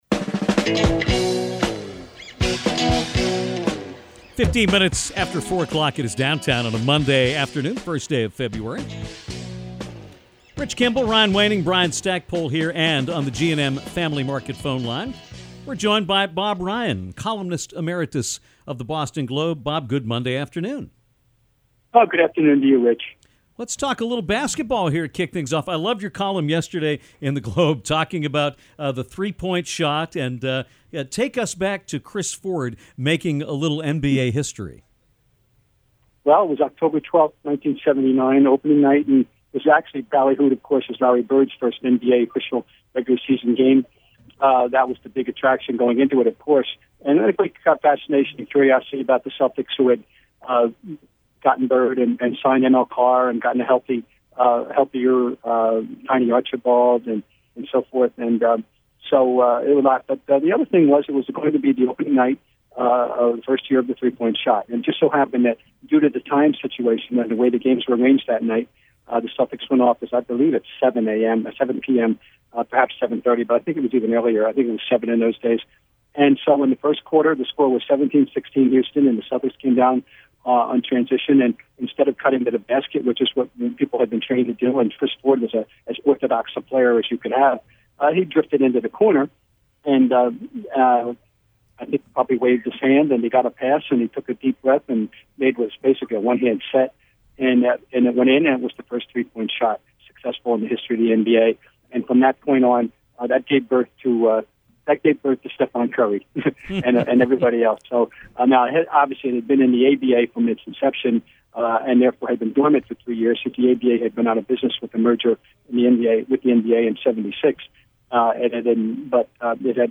Bob Ryan of the Boston Globe joined Downtown to talk about a wide range of topics, including his recent column for the Globe about the origins of the NBA three-pointer. Ryan remebered watching the first ever three point shot from Celtic Chris Ford, one of only two in the first game when it became the rule. Bob shared his thoughts on the three point play today, especially his disdain for the play among young players.